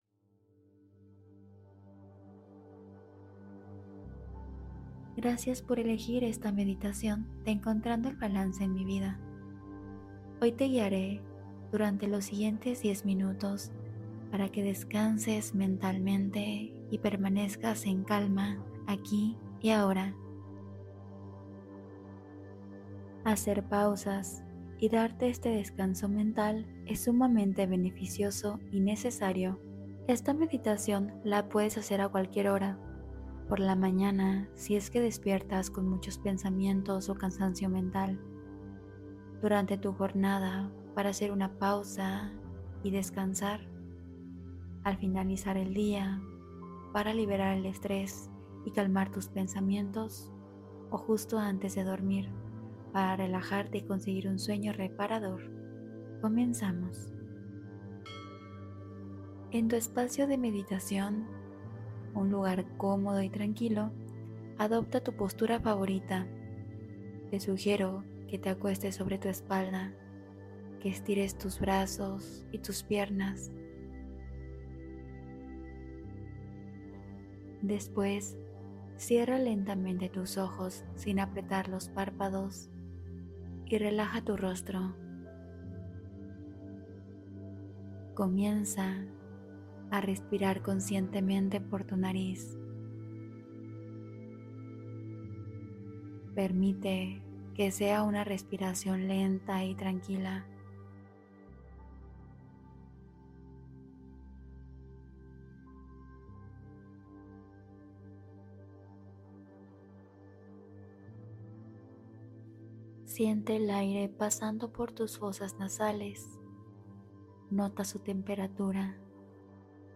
Relaja tu Mente: Meditación Suave de 10 Minutos